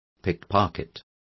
Also find out how chorizos is pronounced correctly.